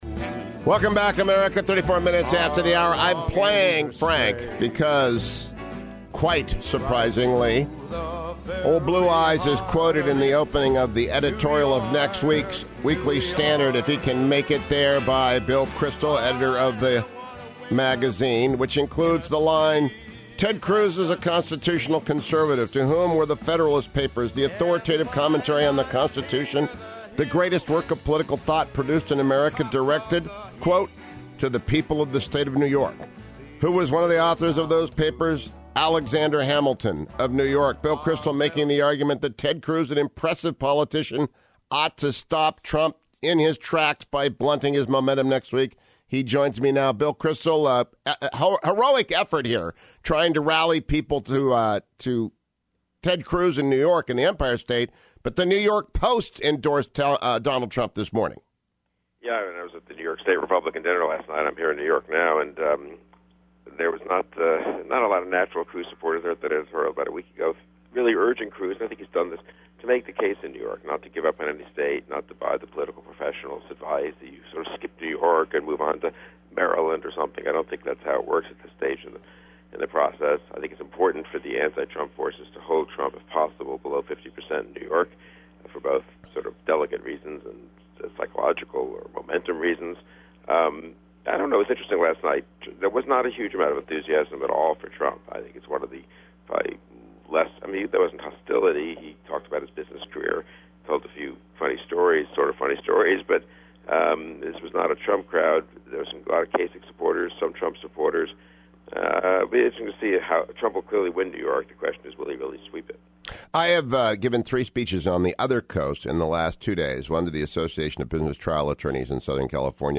Weekly Standard editor Bill Kristol joined me in the first hour of Friday’s program to discuss Thursday night’s Democratic debate and the three speeches by the GOP contenders across town at the same time at the NY GOP state dinner: